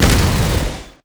ZombieSkill_SFX
sfx_skill 06.wav